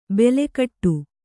♪ bele kaṭṭu